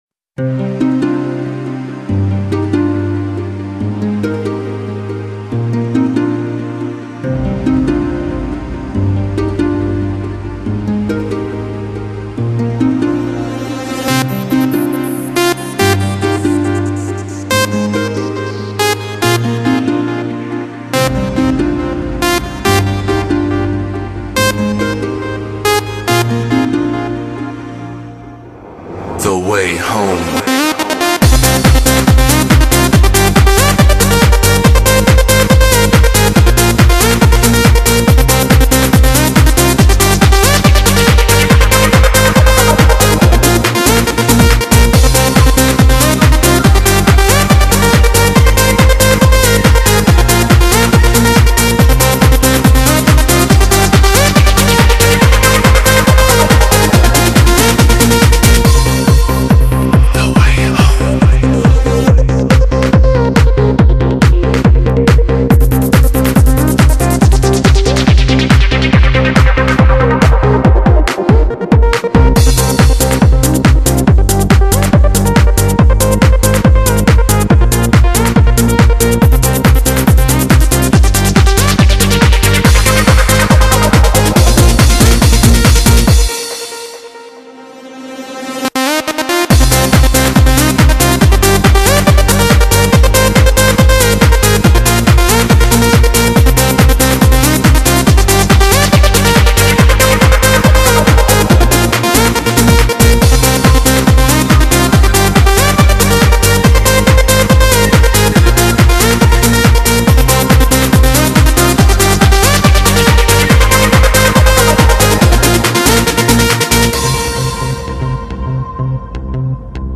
Kazantip_Klubnaja_muzyka__im.mp3